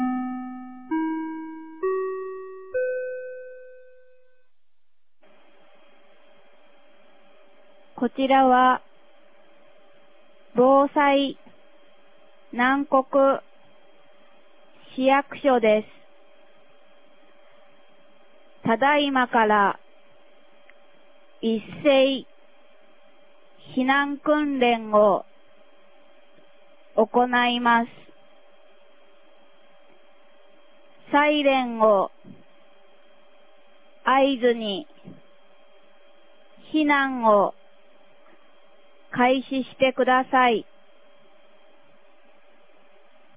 2022年11月06日 09時00分に、南国市より放送がありました。